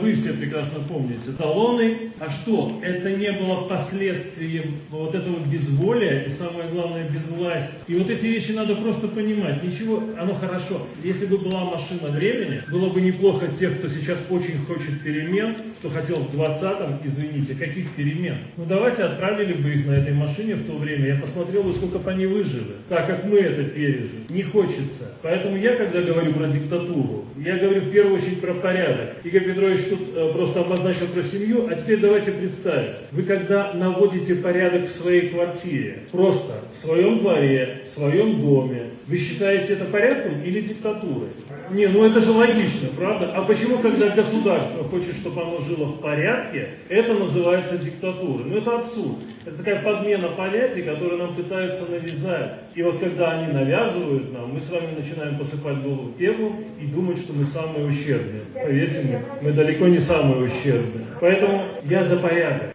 В рамках акции «Марафон единства» в Барановичах  состоялась «Знаковая встреча» с  известными спикерами.  На железнодорожном вокзале станции Барановичи-Полесские участники диалога обсуждали актуальные вопросы социальной политики, информационной безопасности, события, происходящие в мире.